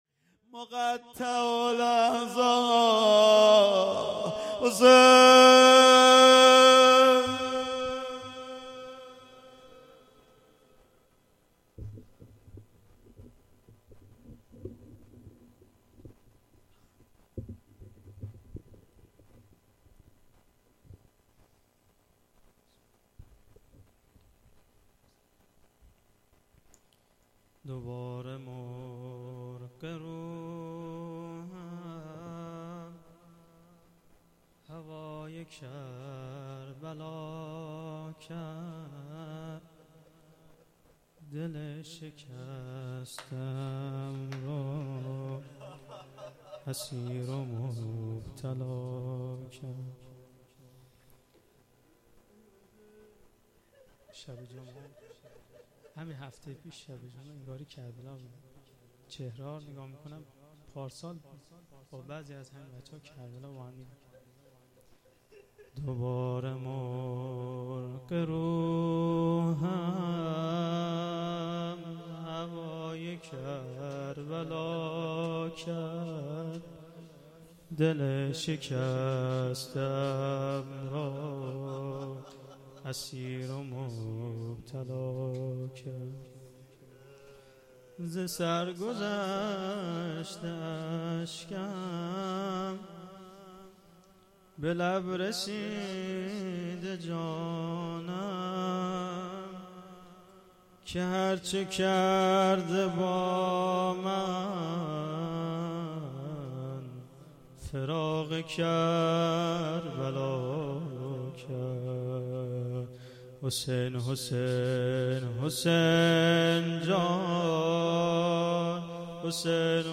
روضه
جلسه هفتگی ۱۵ مهر ۹۹